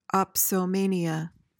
PRONUNCIATION:
(op-so-MAY-nee-uh)